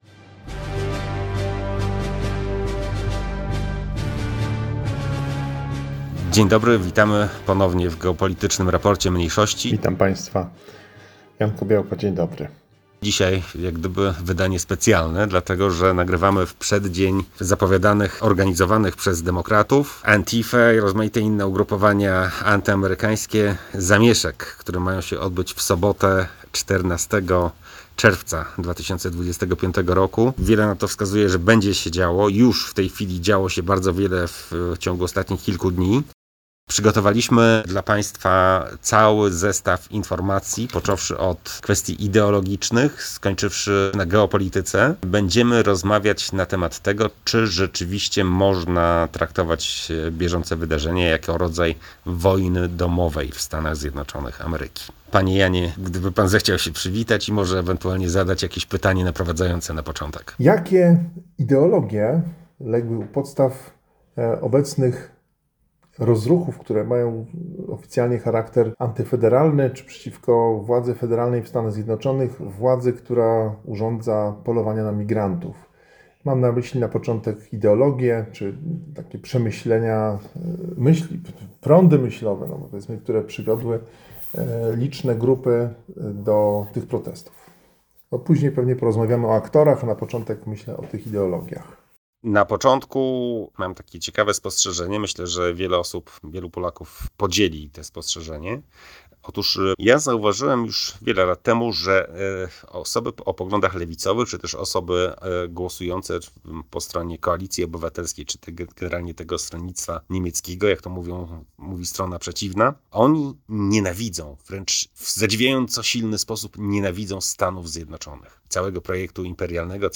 GRM-s01e08--Wojna-domowa-USA-cz2-rozmowa.mp3